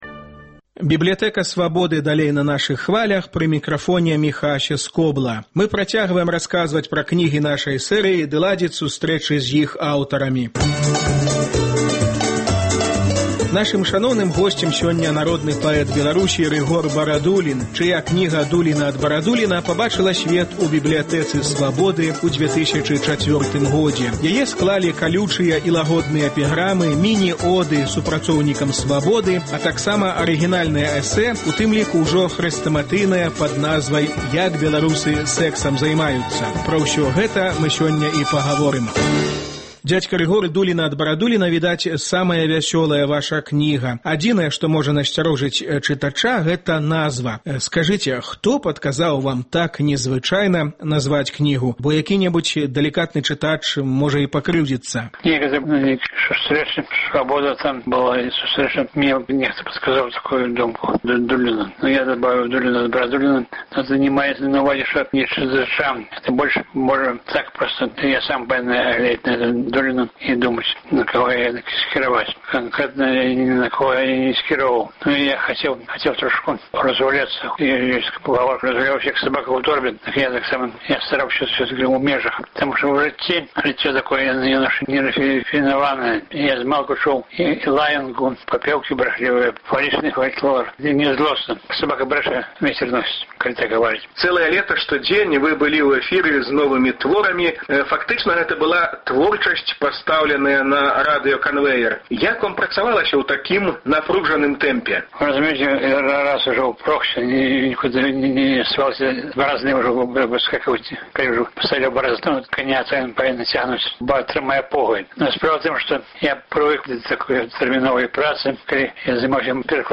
"Дуліна ад Барадуліна". Гутарка з Рыгорам Барадуліным.